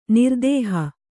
♪ nirdēha